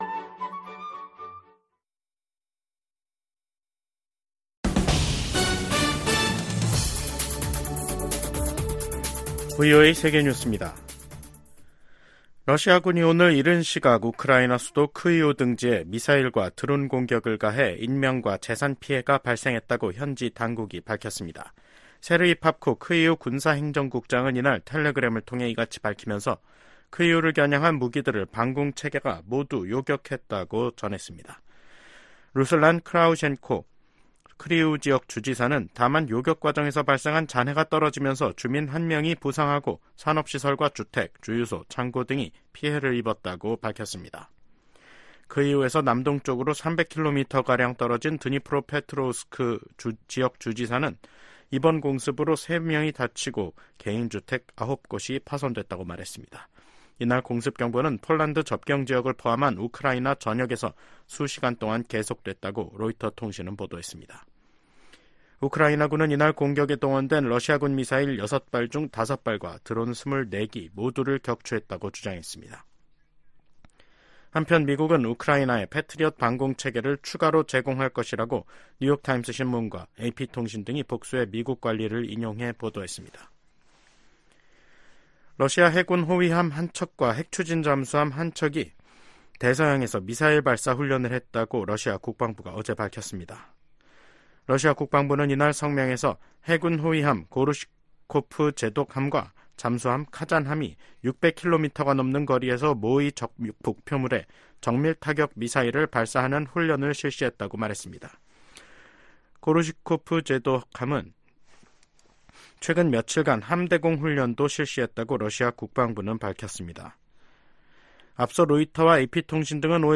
VOA 한국어 간판 뉴스 프로그램 '뉴스 투데이', 2024년 6월 12일 2부 방송입니다. 미국은 오물 풍선 살포 등 한반도 안보와 관련해 “어떤 일에도 준비가 돼있다”고 필립 골드버그 주한 미국대사가 말했습니다. 풍선 살포로 한반도에 긴장이 고조된 가운데 남북한은 확전을 피하기 위해 상황을 관리하려는 움직임을 보이고 있습니다.